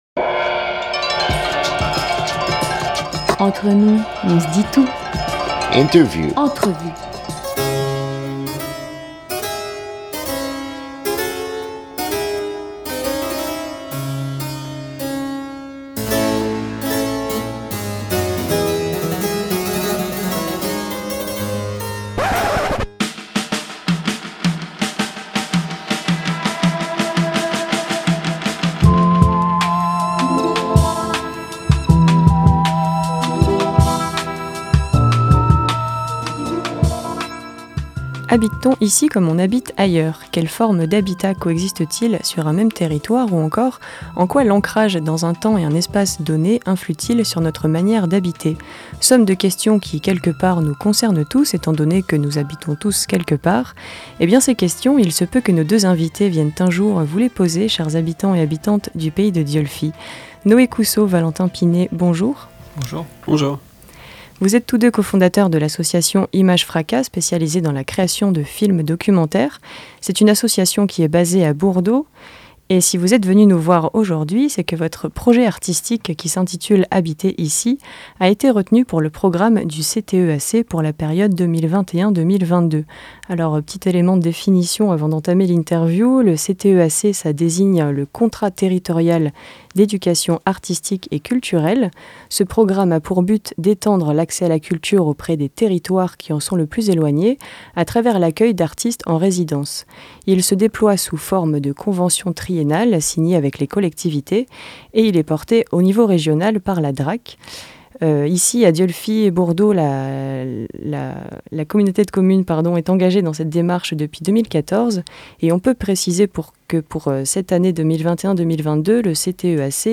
13 décembre 2021 15:00 | Interview